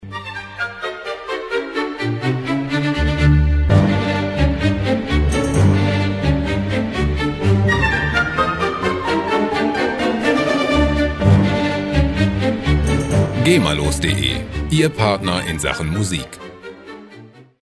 Gema-freie Klassik Loops
Musikstil: Romantik
Tempo: 127 bpm